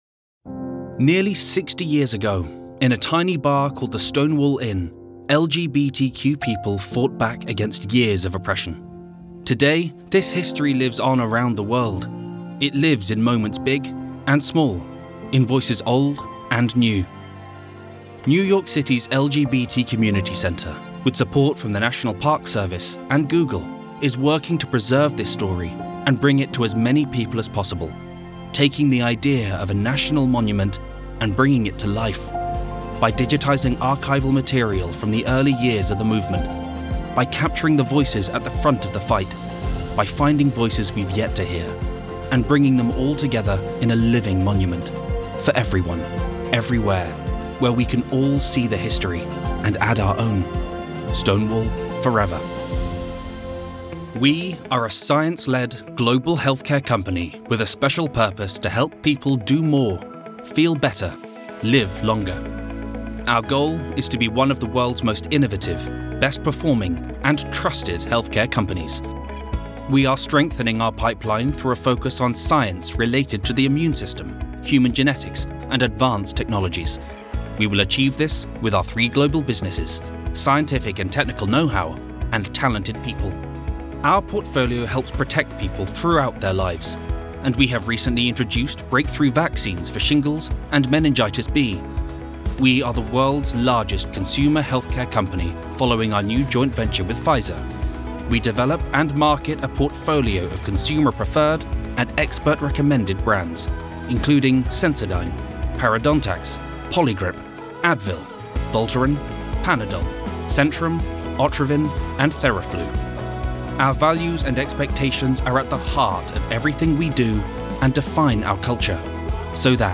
Corporate Showreel
Male
Neutral British
British RP
Cool
Youthful
Confident
Friendly